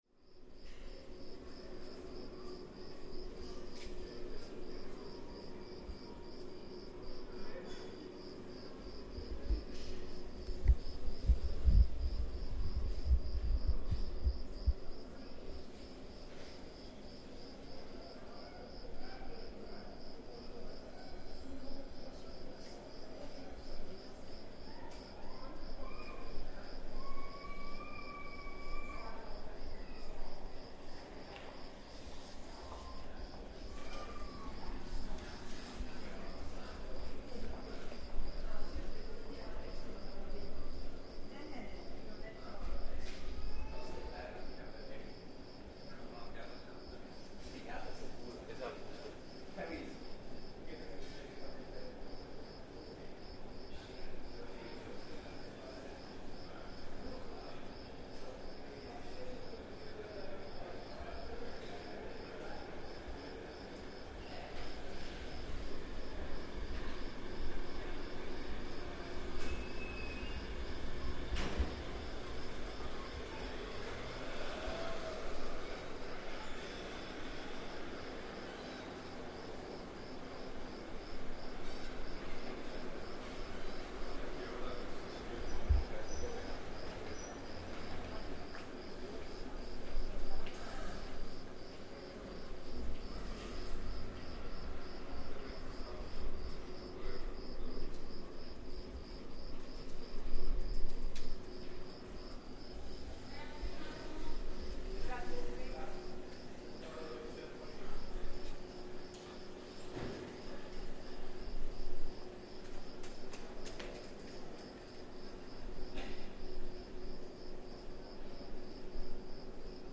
Cicadas vs. air conditioning
The somewhat unusual sound of cicadas audible in the centre of a city during late morning, as their chirping competes with air conditioning sounds from a neighbouring building.
Recorded in Treviso, Italy by Cities and Memory.